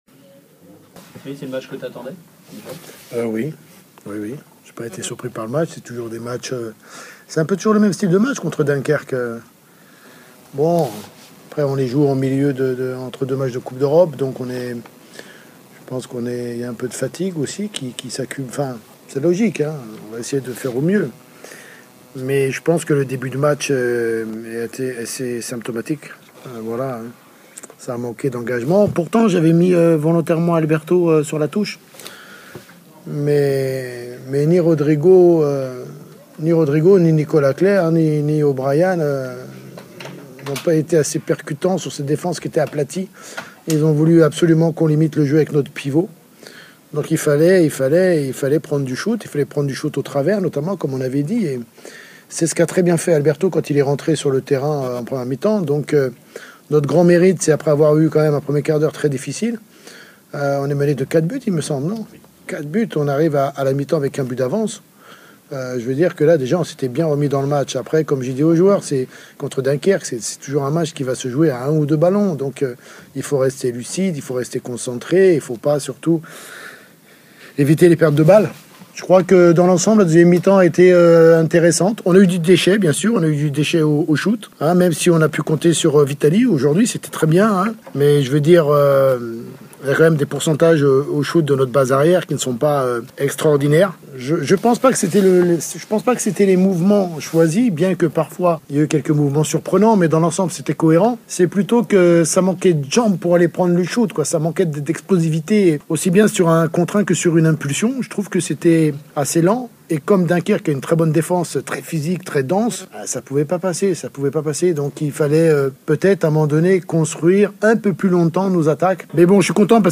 conf_apres_match_hbc_-dunkerque.mp3